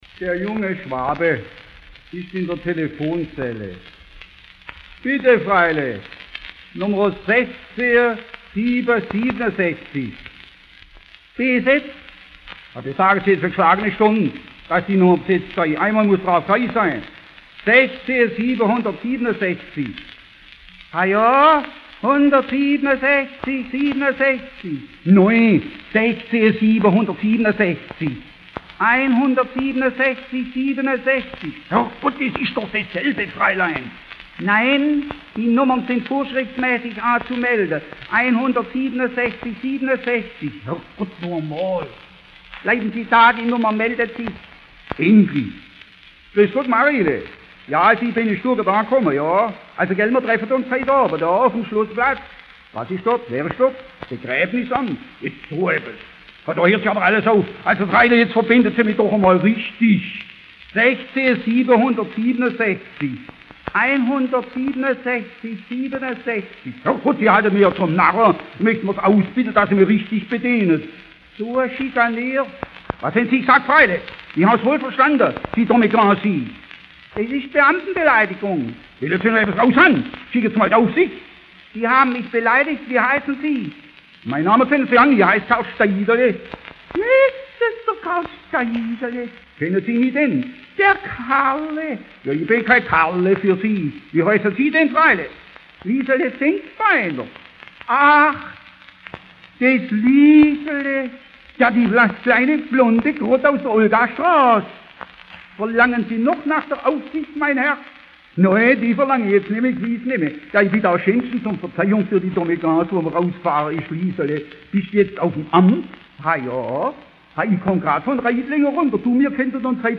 Quelle: Schellackplattensammlung Schw�bisches Kulturarchiv